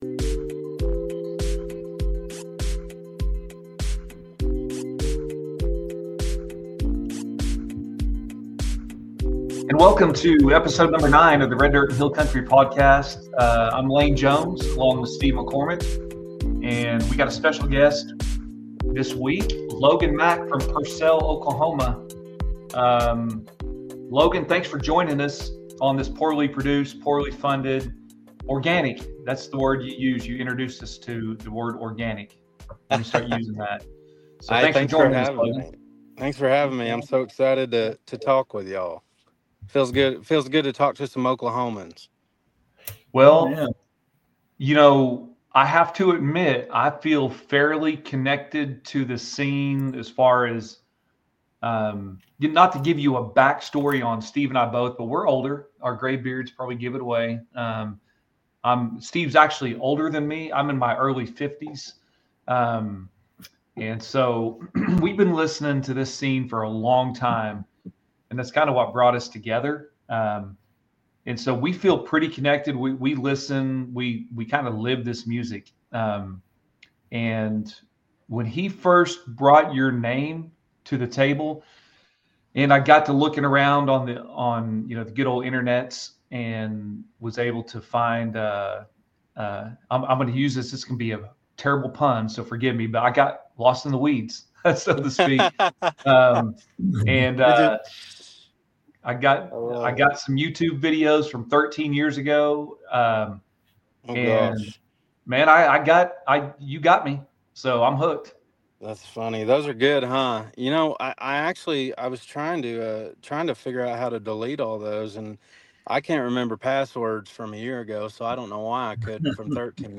2 average, middle aged dudes talking about roots music in Oklahoma and Texas.